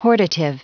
Prononciation du mot hortative en anglais (fichier audio)